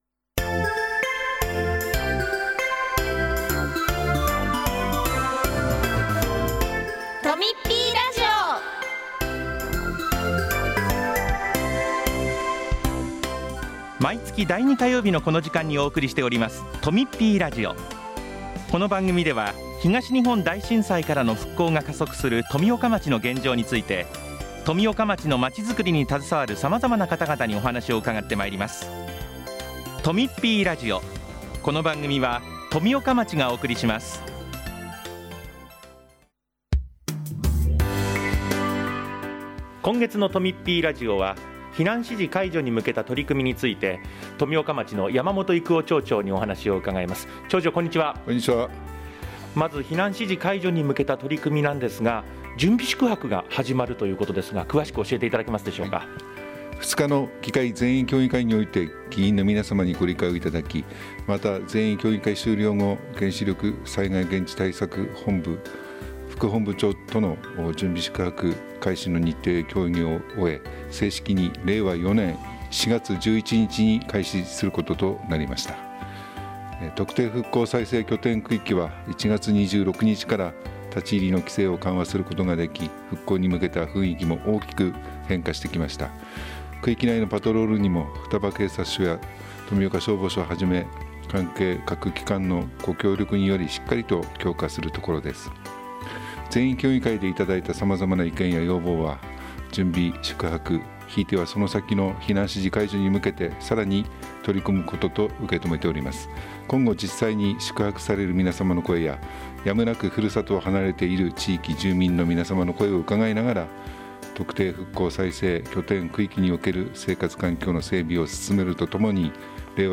3月8日（火曜日）に放送したとみっぴーラジオをお聴きいただけます。
今月は山本育男町長が「避難指示解除に向けた取組」について話をします。